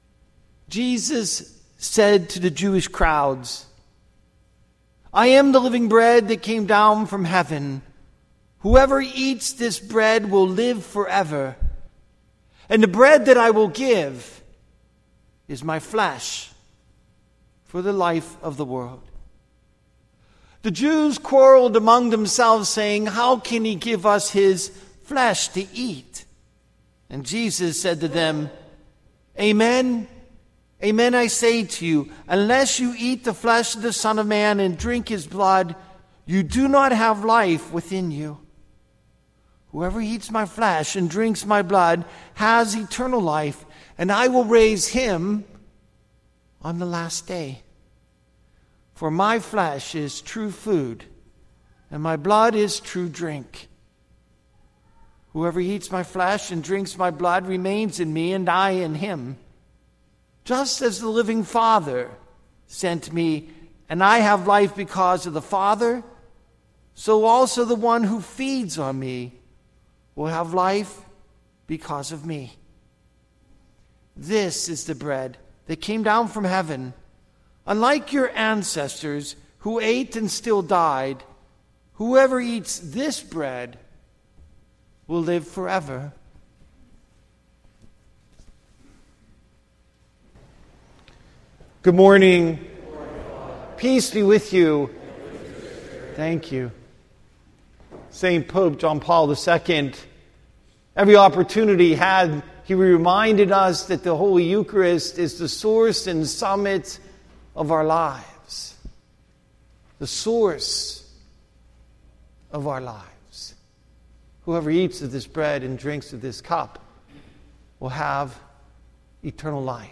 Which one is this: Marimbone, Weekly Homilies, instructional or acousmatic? Weekly Homilies